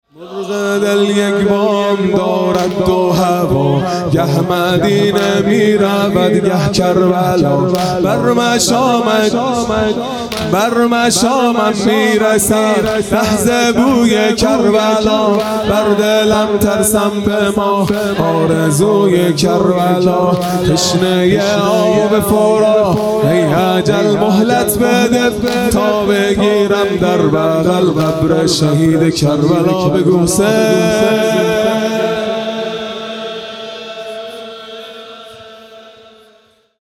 عزاداری دهه آخر صفر المظفر (شب دوم)